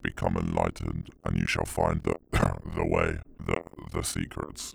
EndlessVendetta / Voice Lines
Become enlightened and you shall find the way.wav